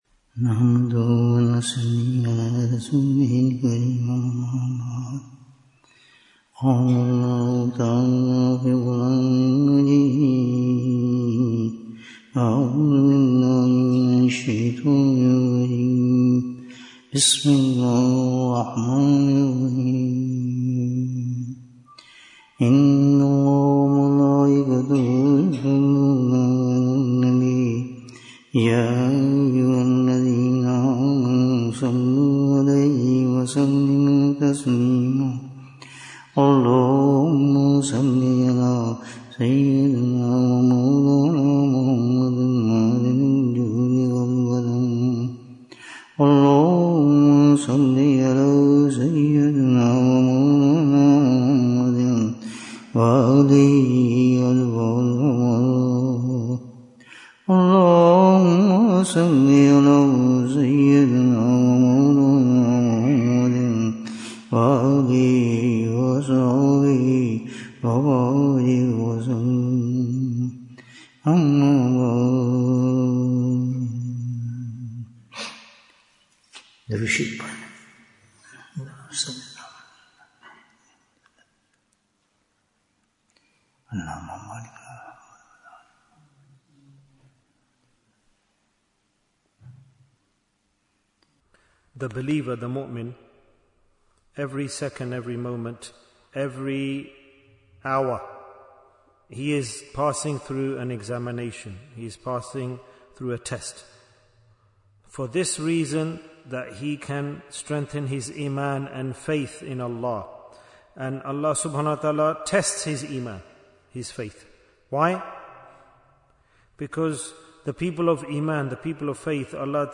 The Causes for Afflictions & the Remedy Bayan, 78 minutes24th April, 2025